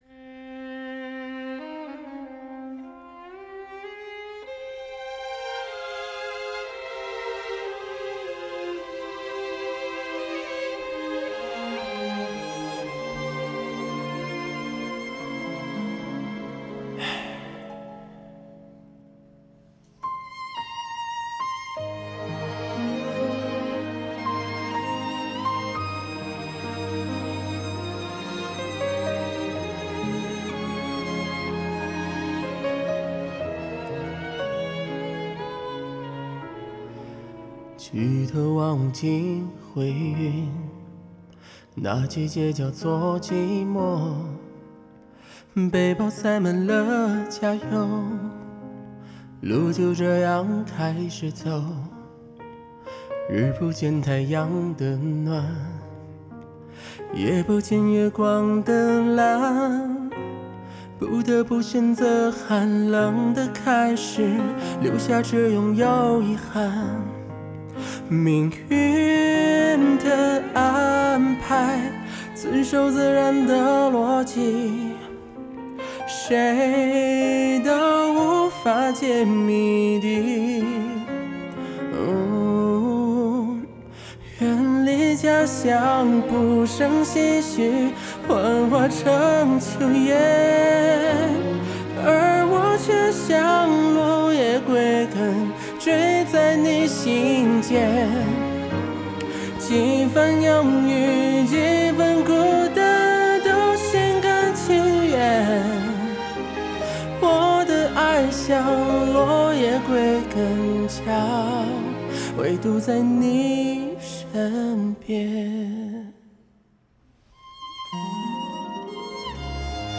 还是刚吃完饭随便整几句，凑合听。